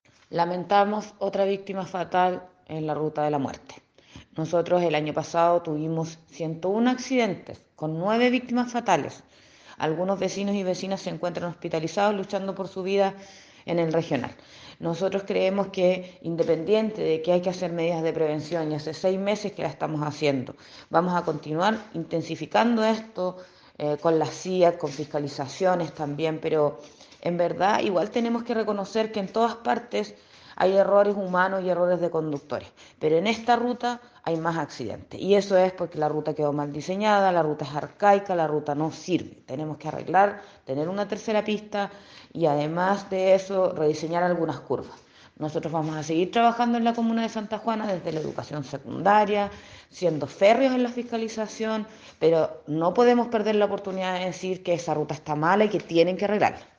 En tanto, la alcaldesa de Santa Juana, Ana Albornoz, dialogó con Bío Bío en Concepción lamentando que nuevamente la ruta de la Madera sea escenario de un accidente de tránsito con resultado fatal.